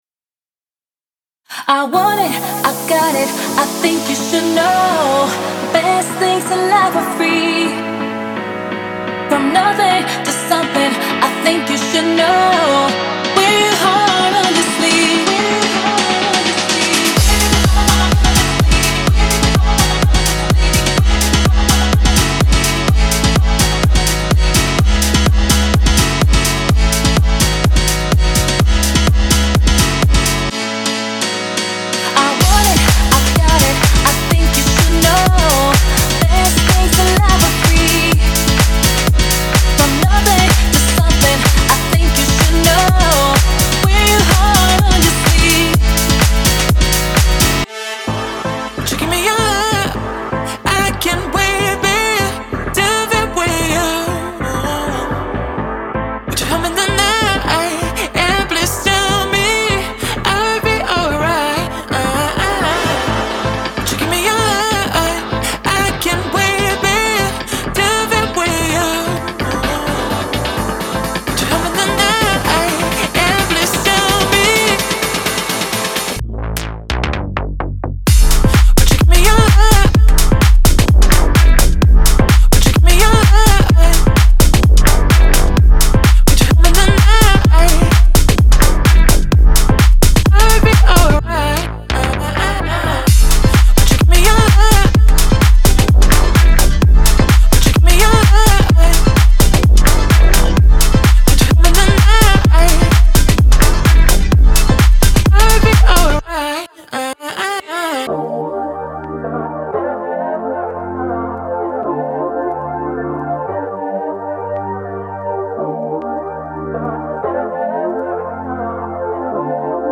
Type: Midi Samples
House Tech House Vocals